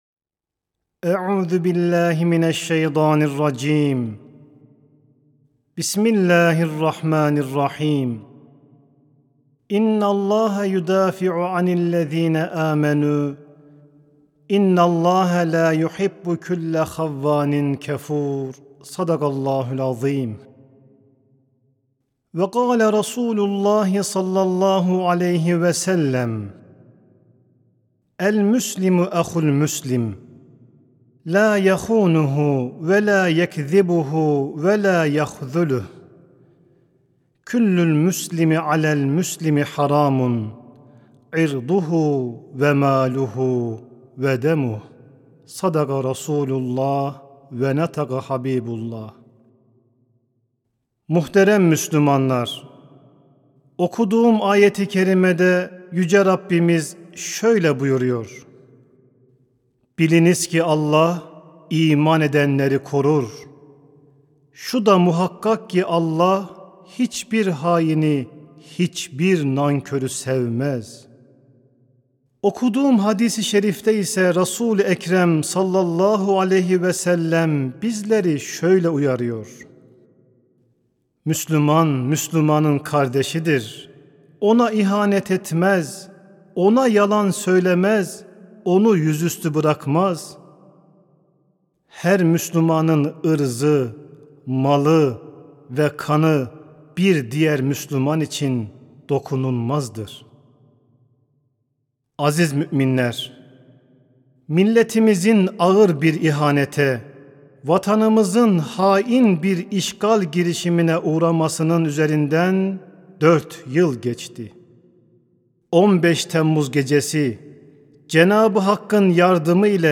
CUMA HUTBESİ: 15 TEMMUZ VE BİRLİK RUHU
15-Temmuz-ve-Birlik-Ruhu-Sesli-Hutbe.mp3